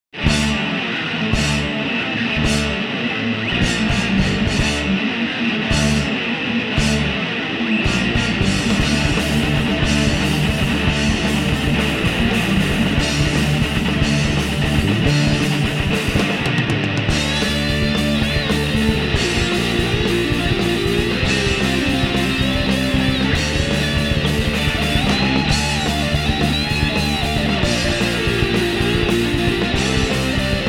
is a short song, but a hymn for the heavy metal